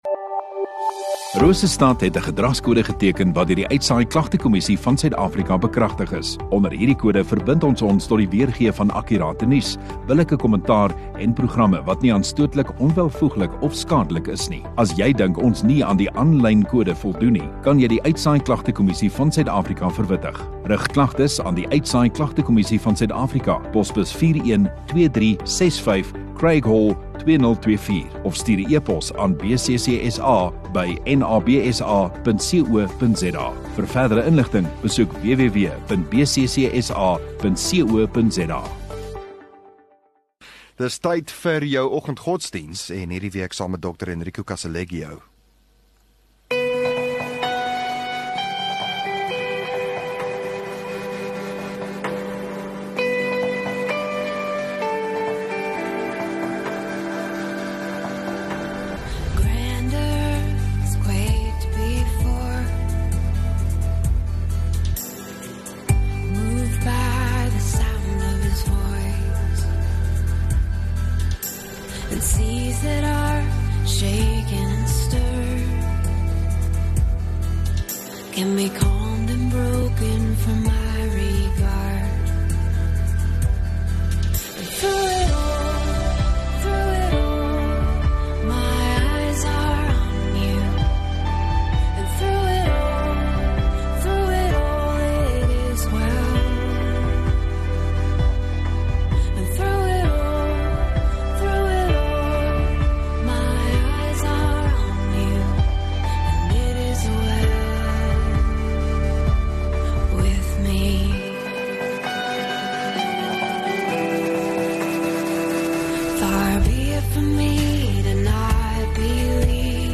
10 Jul Donderdag Oggenddiens